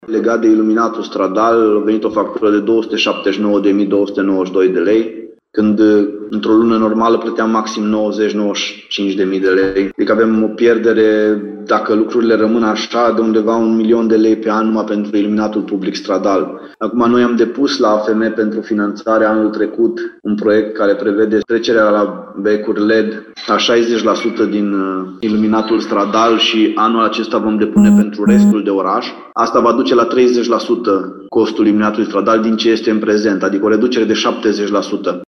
Și pentru iluminatul public din oraș, primarul spune că trebuie achitată o factură record: